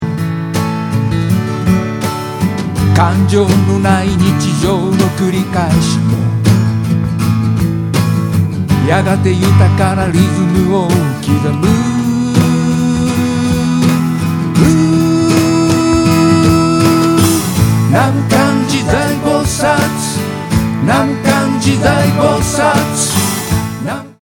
Percussion / Cajon / Cho.
Vo. / A.Guitar
A.Guitar / E.Guitar / Cho.